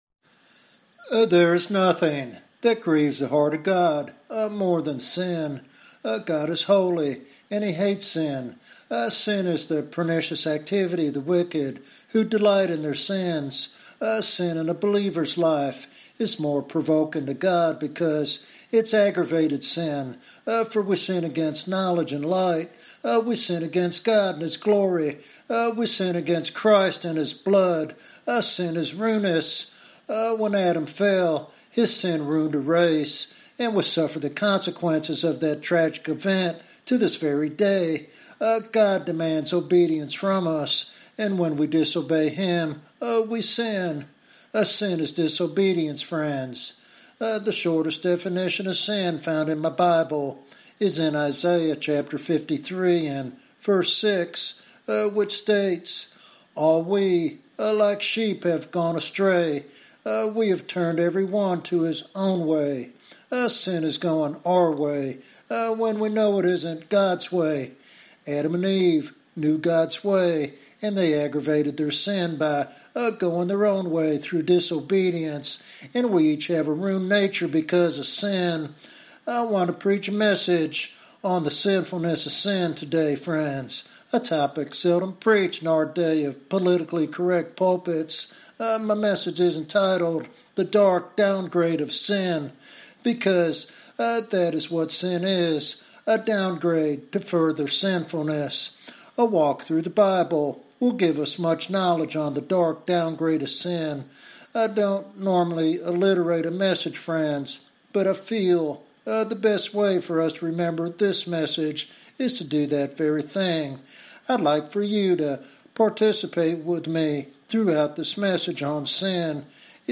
This sermon is a call to holiness and repentance in a world increasingly desensitized to sin.